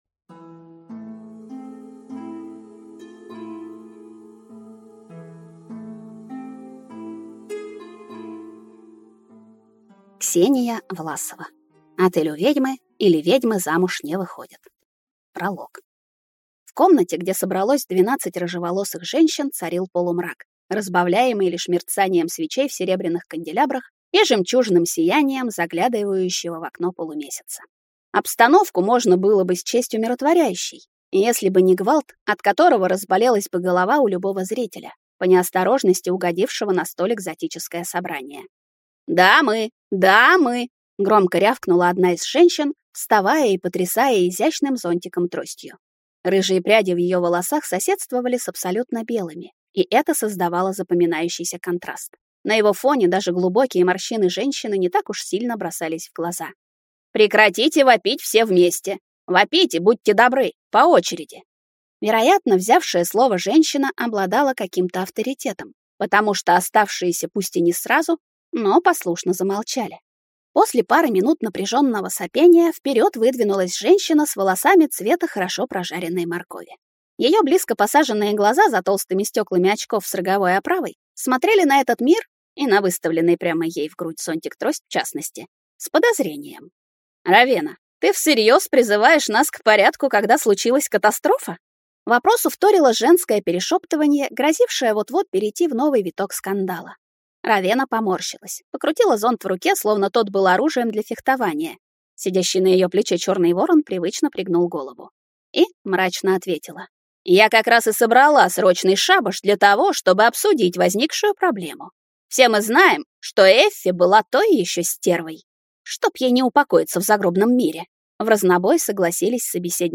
Аудиокнига Отель «У ведьмы», или Ведьмы замуж не выходят!
Прослушать и бесплатно скачать фрагмент аудиокниги